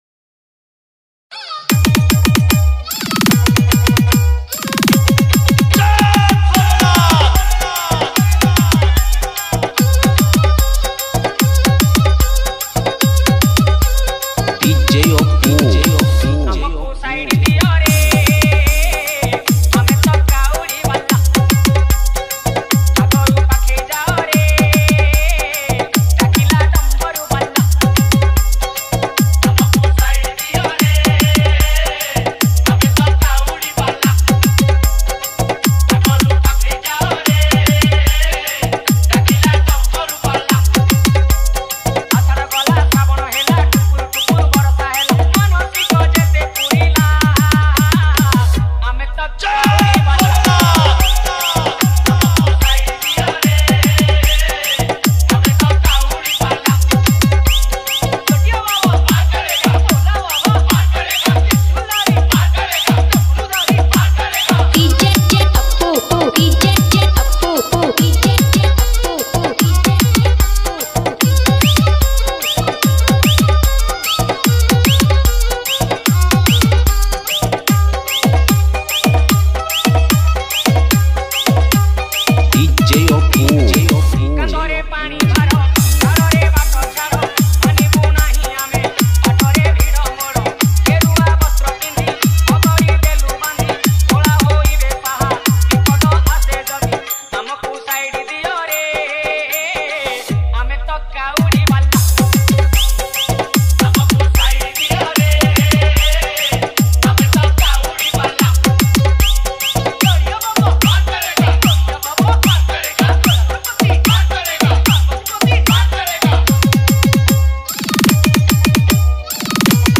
Category:  Odia Bhajan Dj 2020